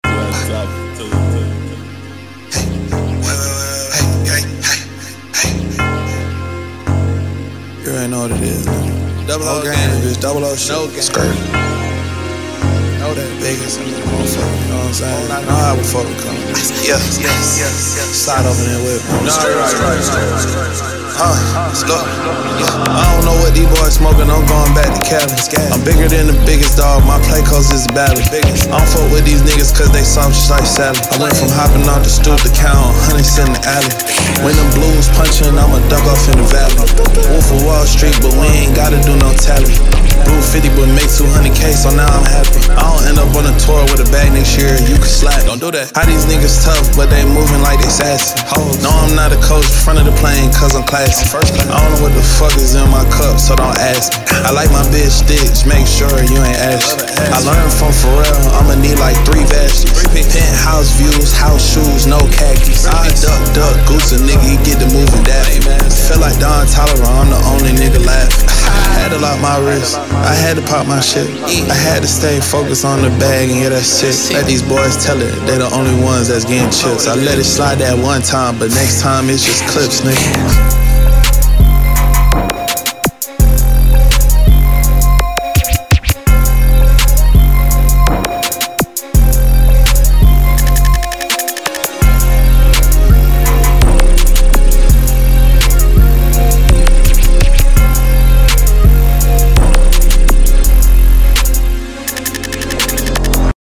Трек размещён в разделе Рэп и хип-хоп / Зарубежная музыка.